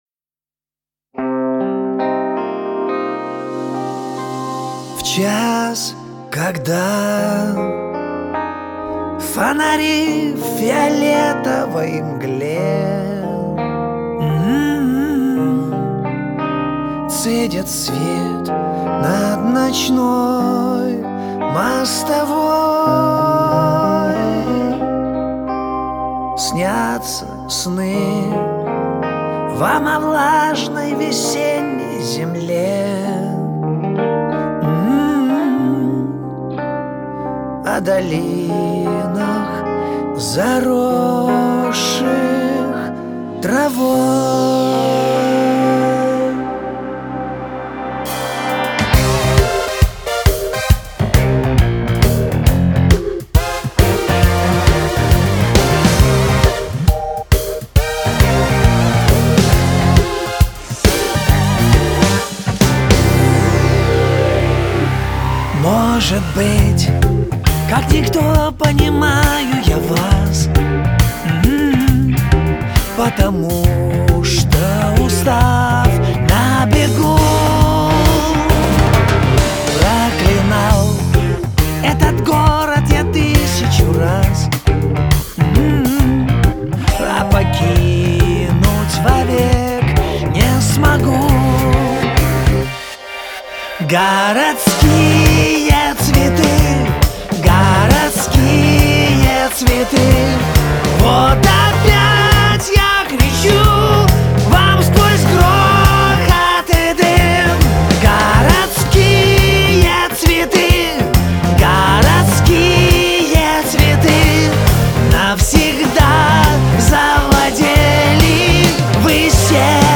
Русский Рок
кавер-версия